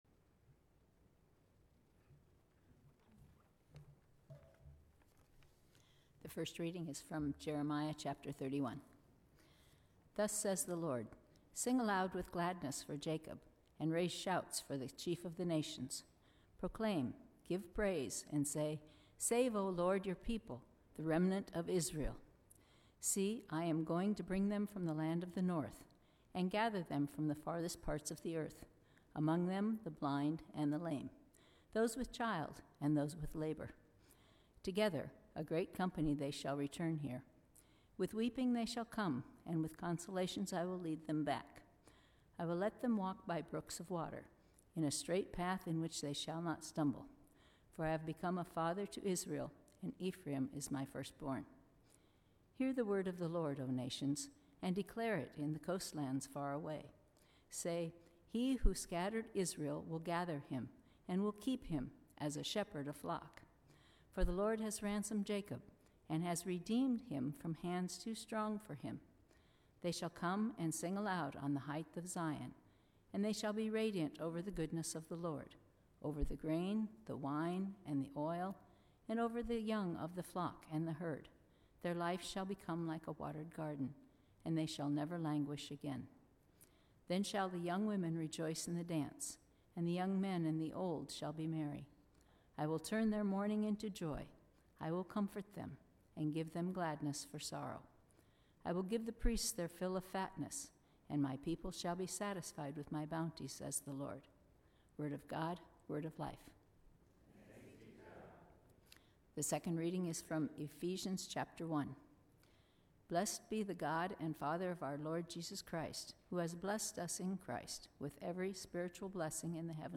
Jeremiah 31:7-14; Ephesians 1:3-14; John 1:1-18 Sermon